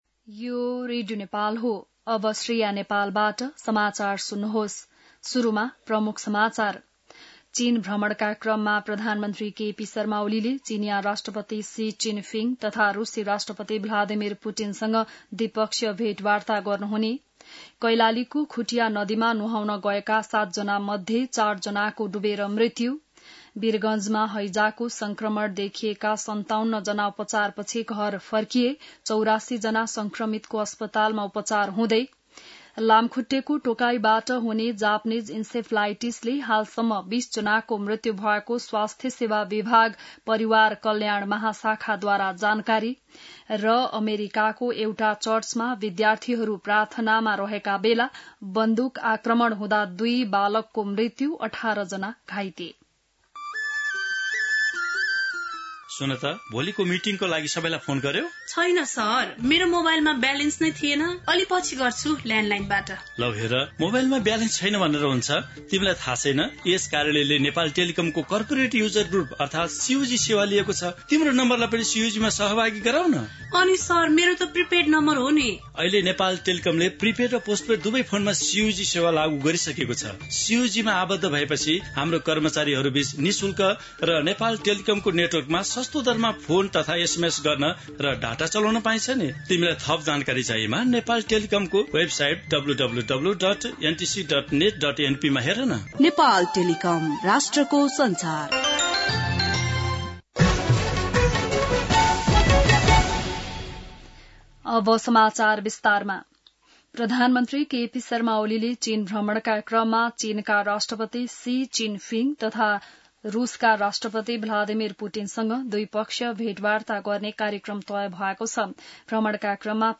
बिहान ७ बजेको नेपाली समाचार : १३ भदौ , २०८२